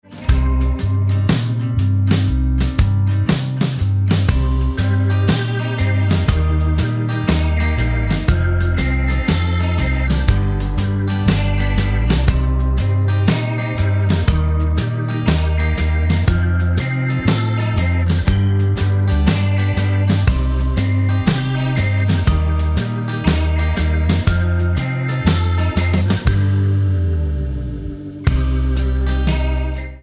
Tonart:Cm ohne Chor